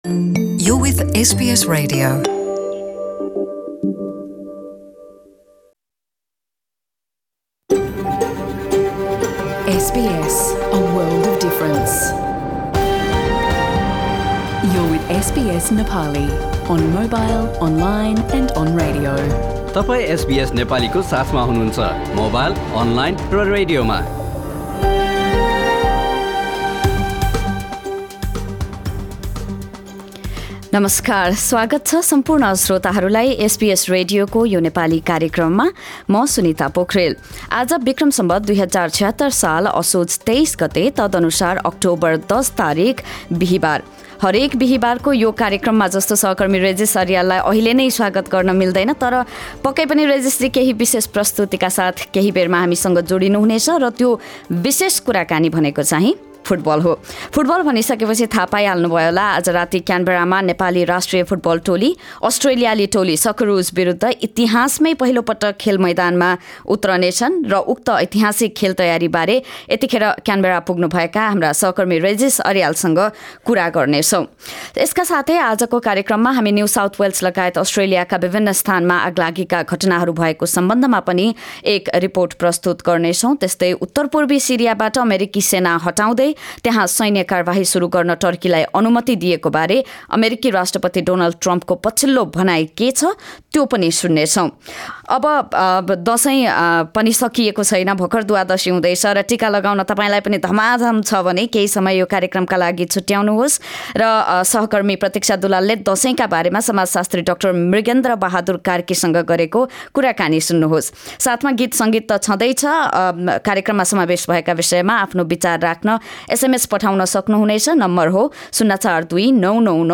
एसबीएस नेपाली अस्ट्रेलिया समाचार: १० अक्टोबर २०१९, बिहीबार